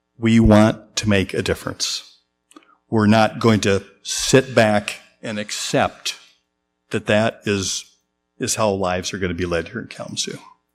He went further at Monday night’s Kalamazoo City Commission Meeting, asking “What are we going to do to reduce gun violence?”